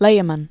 (lāə-mən, lī-) fl. 13th century.